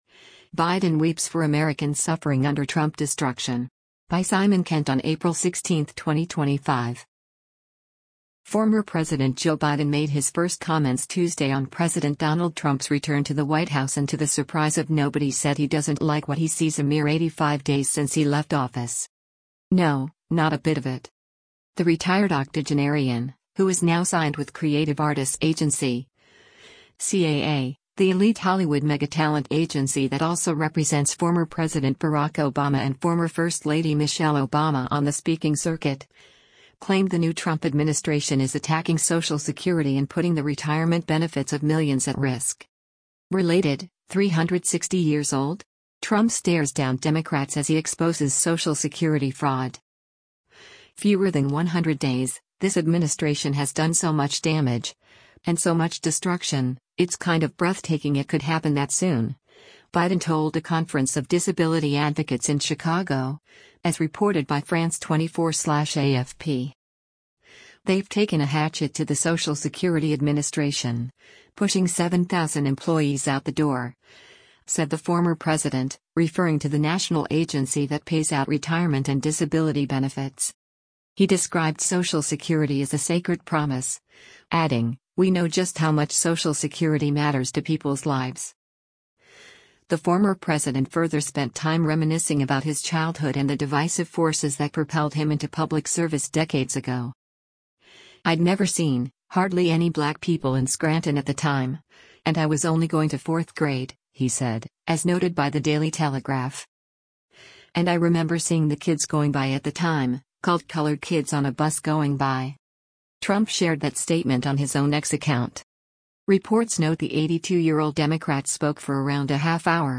CHICAGO, ILLINOIS - APRIL 15: Former U.S. President Joe Biden speaks at a conference hoste
“Fewer than 100 days, this administration has done so much damage, and so much destruction – it’s kind of breathtaking it could happen that soon,” Biden told a conference of disability advocates in Chicago, as reported by France 24/AFP.